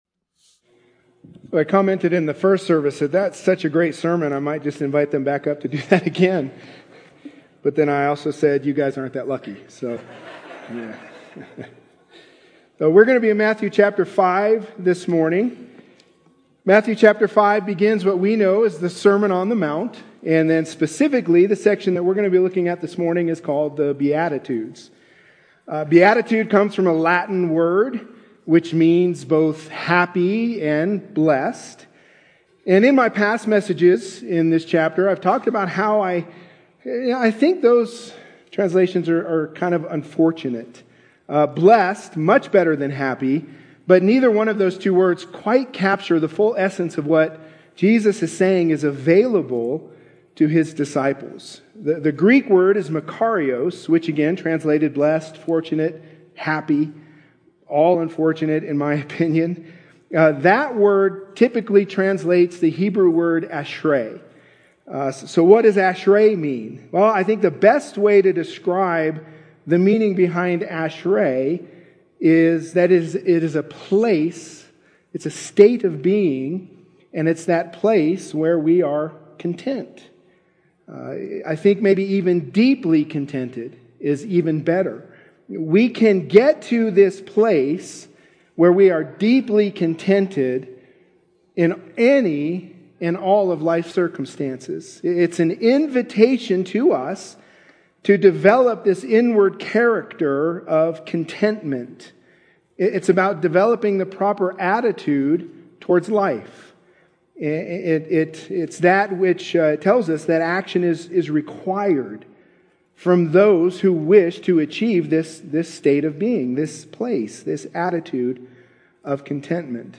Matthew 5:8 Guest Speaker